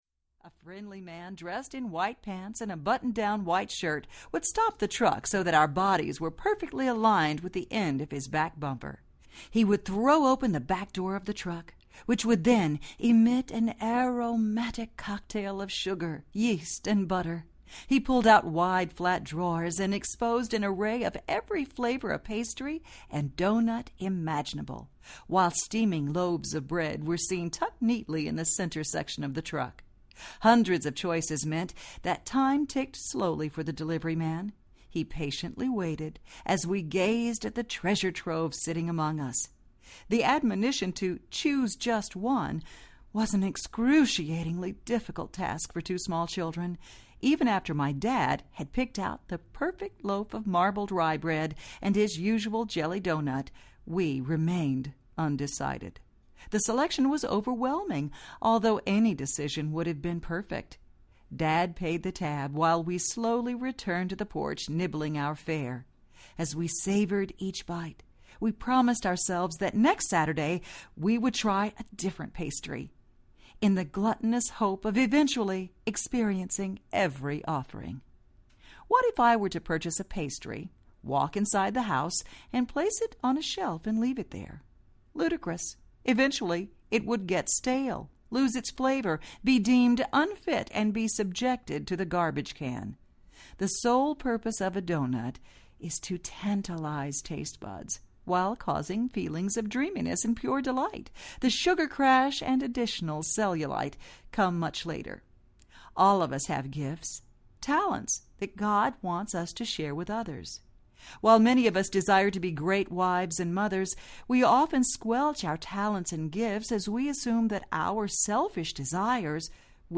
Spilt Milk Audiobook
Narrator
4.4 Hrs. – Unabridged